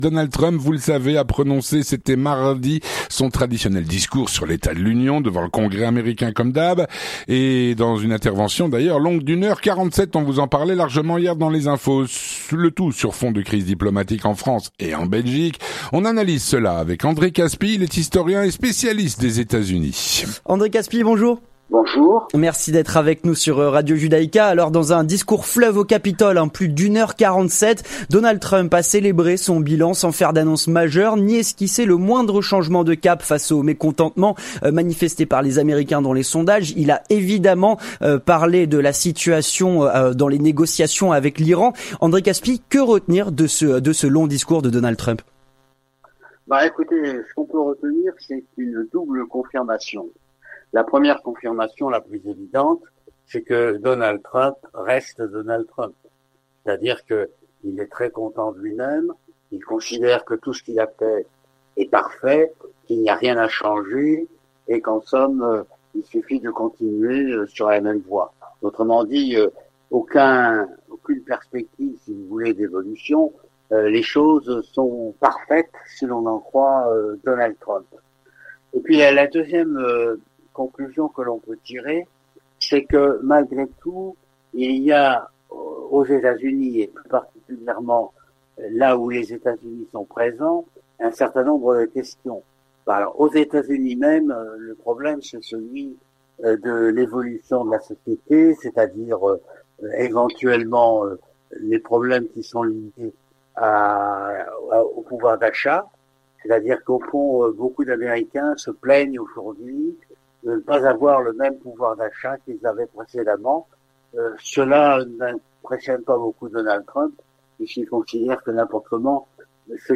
On analyse cela avec André Kaspi, historien, spécialiste des USA.